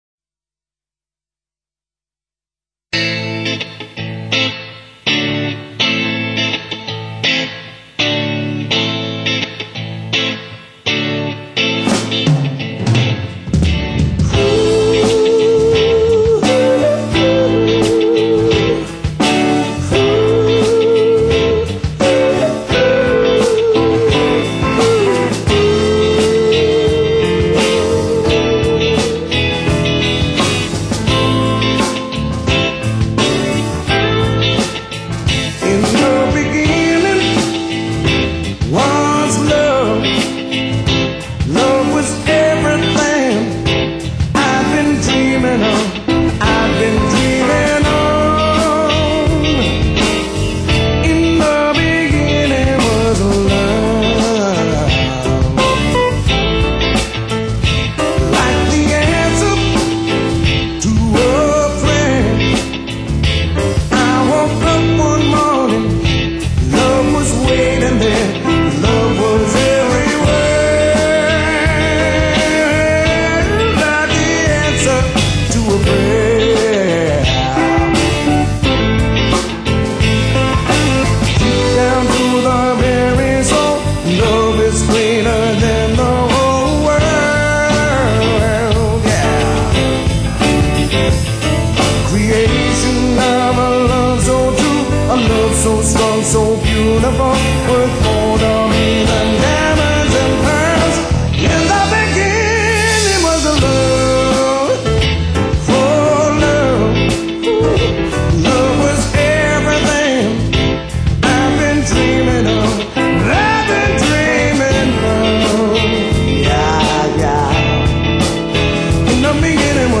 Blues Albums
(1995/R&B)"